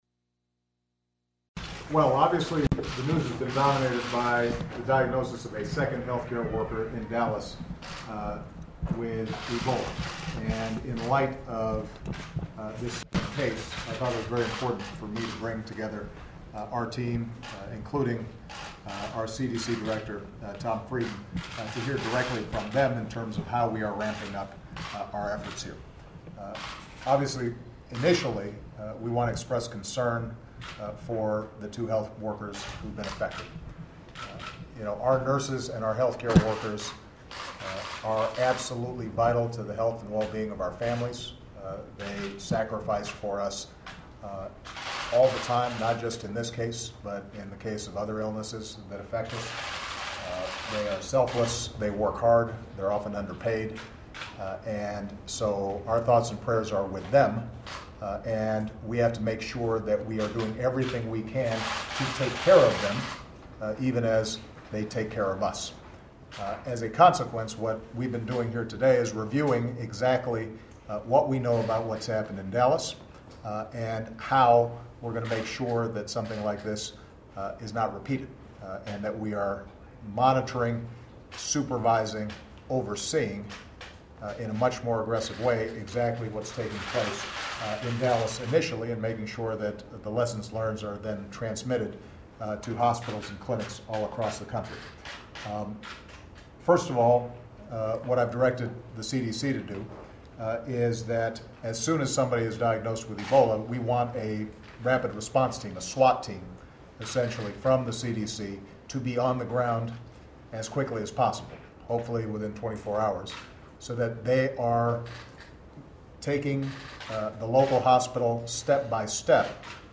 U.S. President Barack Obama speaks to reporters about his briefing with nearly two dozen advisers and aides including Cabinet officials and CDC Director Tom Frieden on the Ebola outbreak in West Africa and cases of the disease in the U
Obama promises additional training and resources to deal with the emergency and describes his efforts to rally assistance from Japan and several European nations. The meeting was called after the discovery of a second Ebola case in the U.S. involving a nurse who traveled by airplane shortly before her symptoms appeared. Held in the White House.